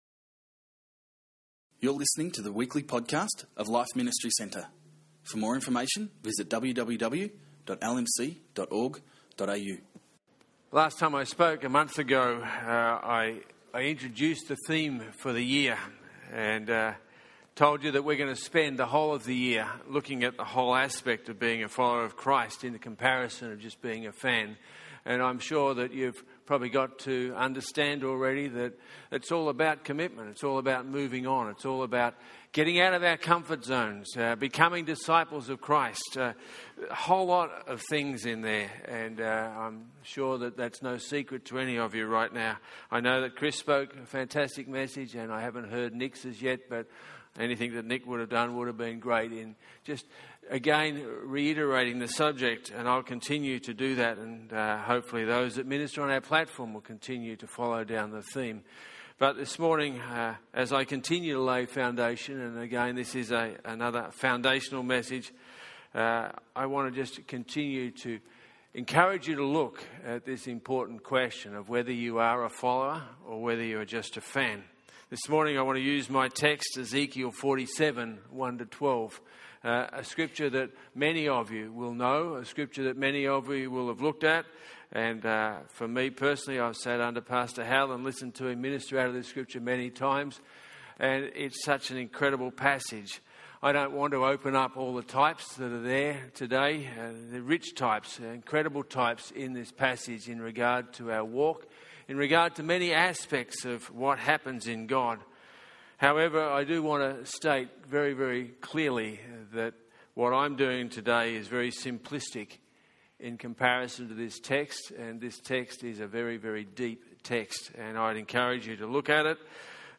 This message is based on the passage from Ezekiel 47:1-12. There is a challenge each step of the way as we go deeper in our relationship with God, but what reward there is for pressing in!